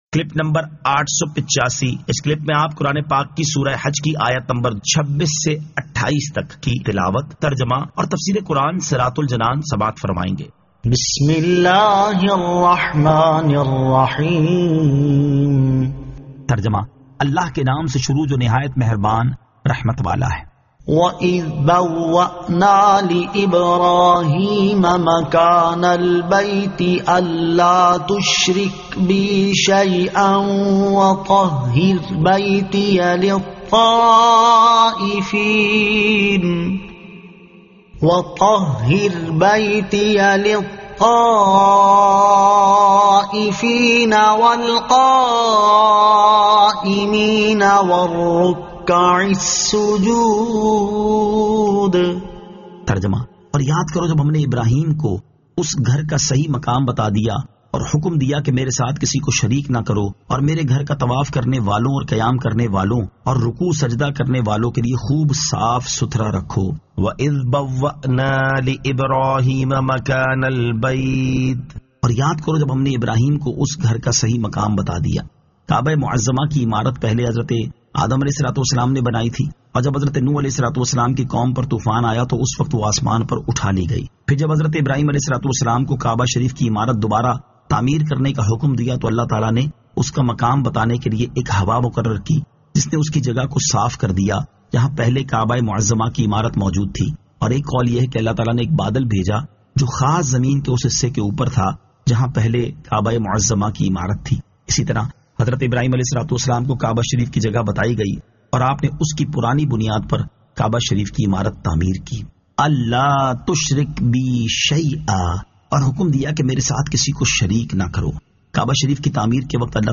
Surah Al-Hajj 26 To 28 Tilawat , Tarjama , Tafseer